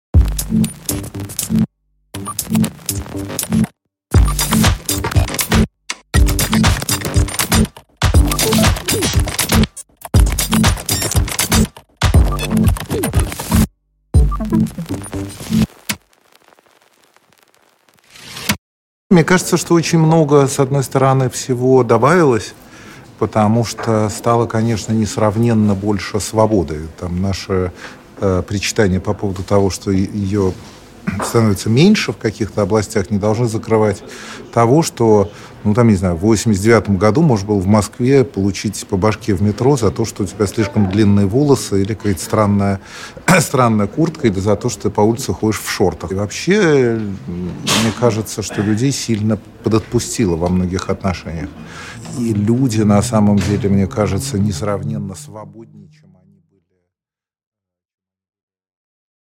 Аудиокнига О стабильности, границах и будущем | Библиотека аудиокниг